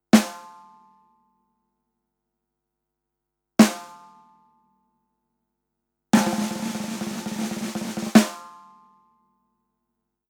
Échantillons sonores Audio Technica AE-5100
Audio Technica AE5100 - werbel perkusyjny